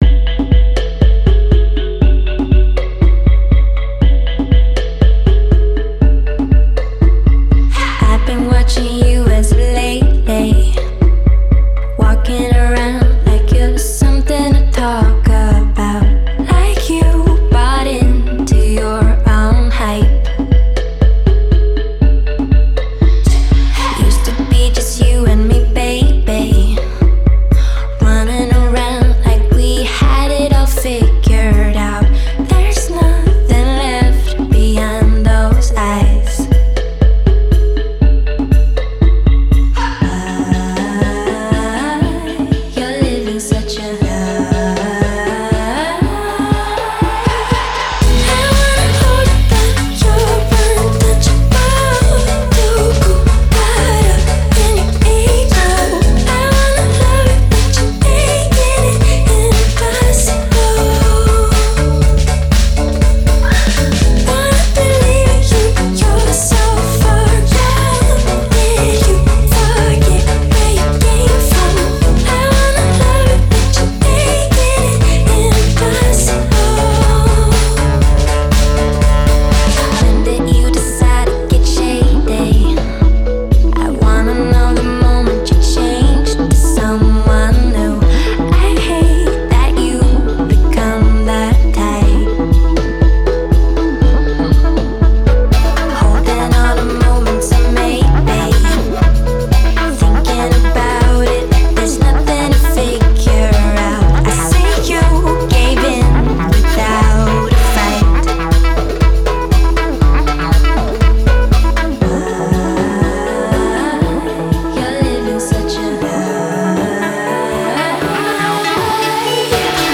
Genre: Pop, Electro-Pop